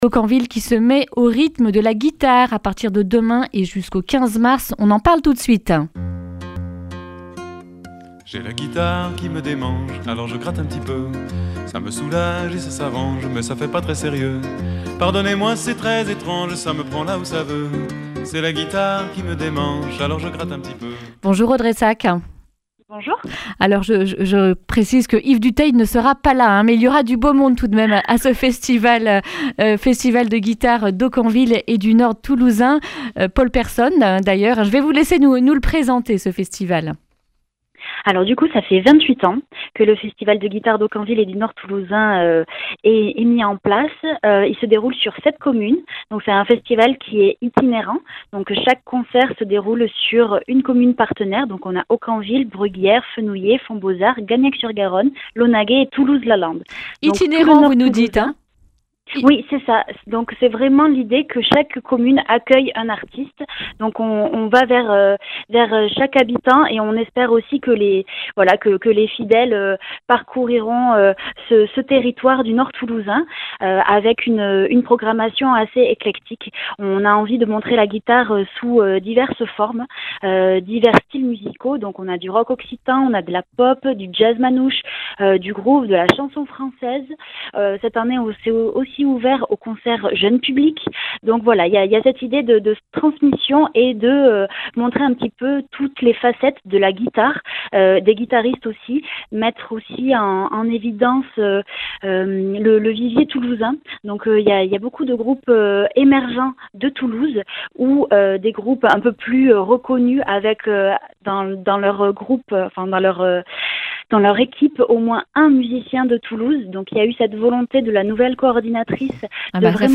mercredi 4 mars 2020 Le grand entretien Durée 11 min